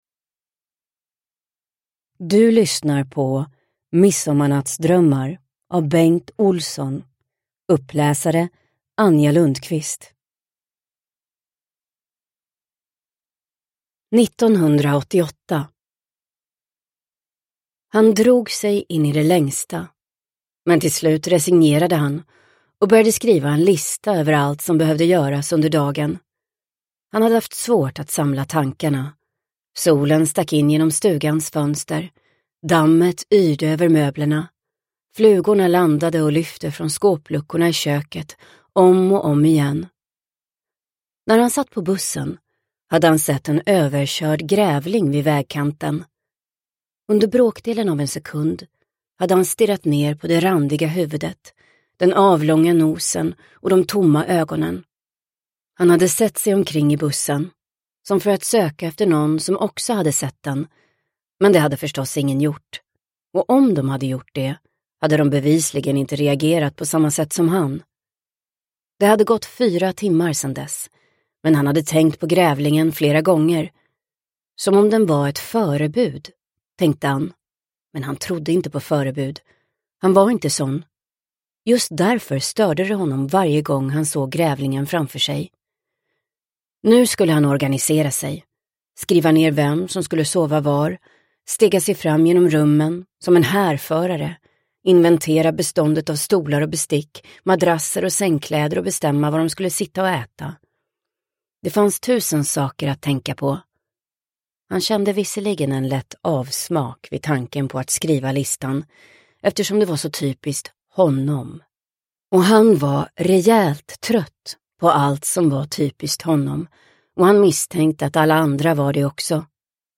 Uppläsare: Anja Lundqvist
Ljudbok